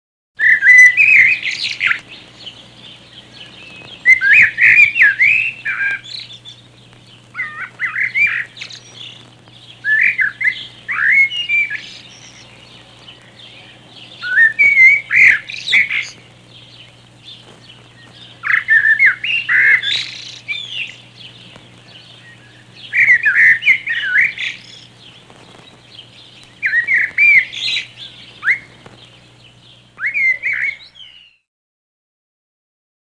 merle | Université populaire de la biosphère
il chante, flûte, siffle, jase, babille
merle.mp3